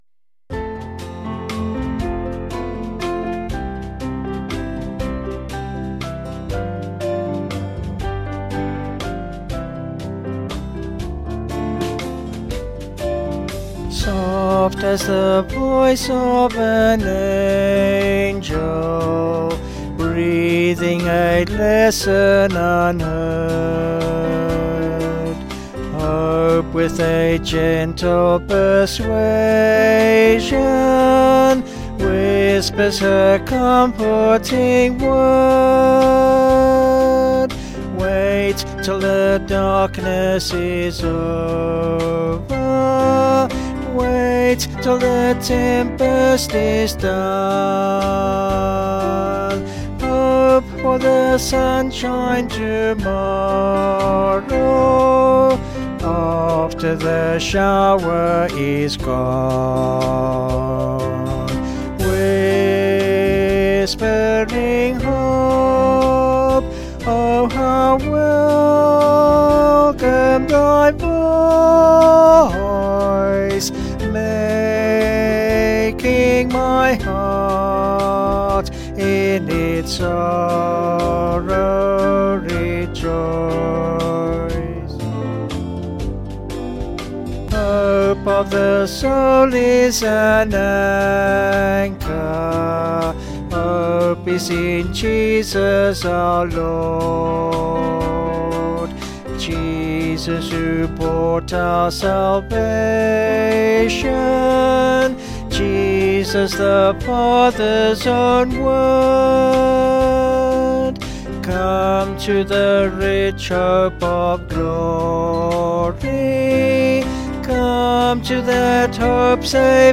Vocals and Band